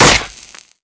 rifle_hit_wood1.wav